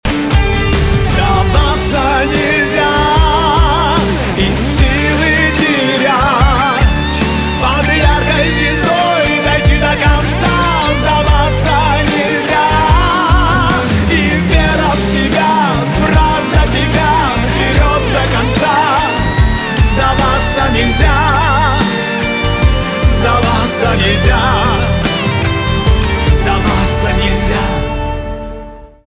поп
чувственные , барабаны , гитара